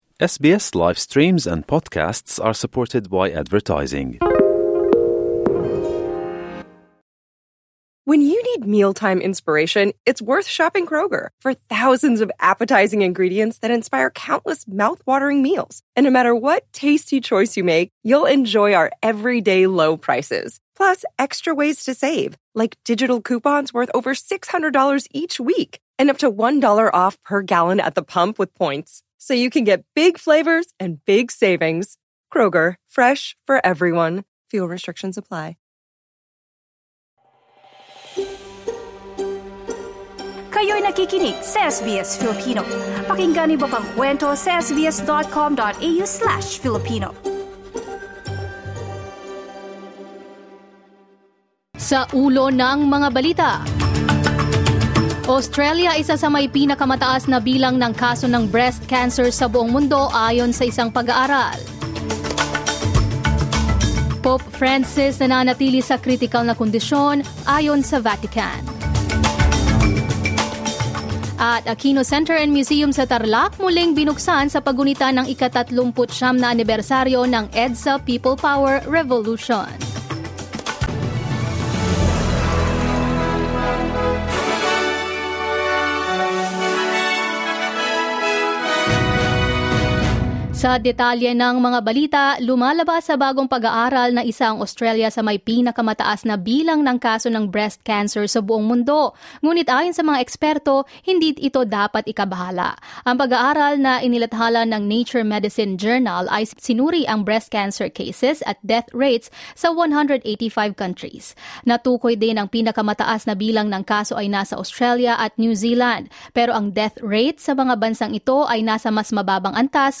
SBS News in Filipino, Tuesday 25 February 2025